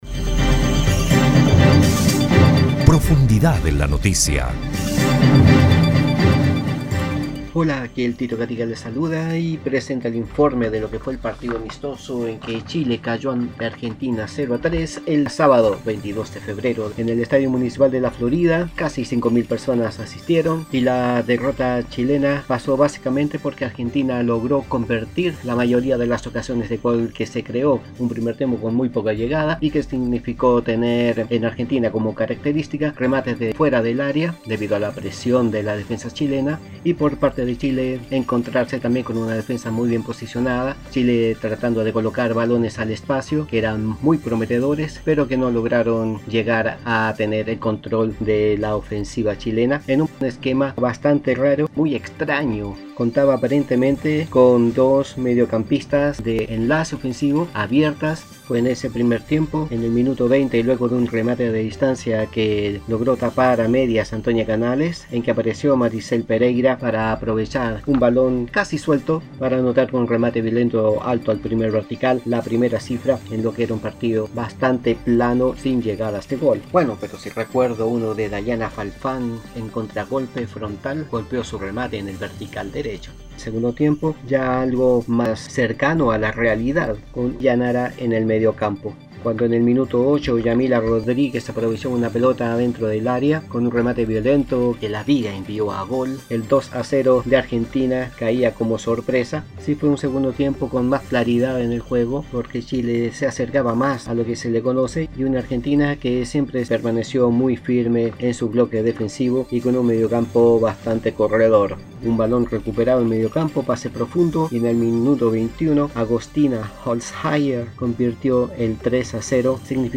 [Podcast / Informe] Selección femenina de fútbol cayó derrotada frente a Argentina